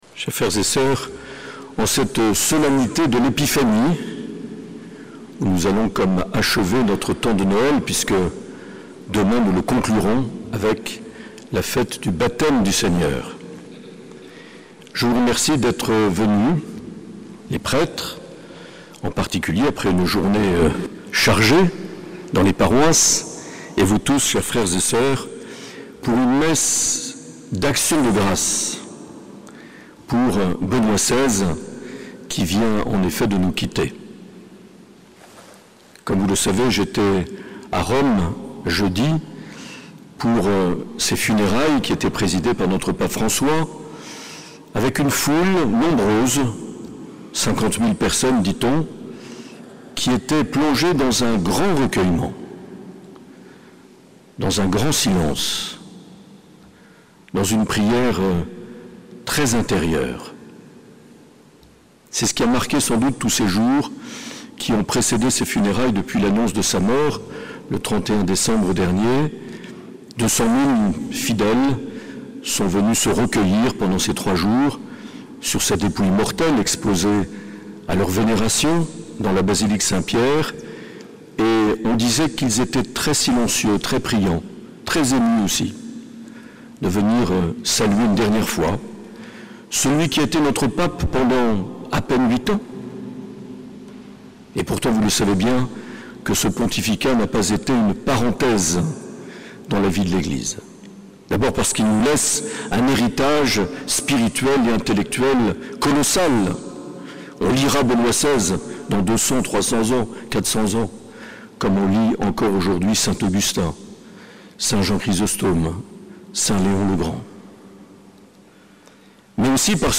Homélie de Mgr Marc Aillet lors de la messe en hommage au pape émérite Benoît XVI.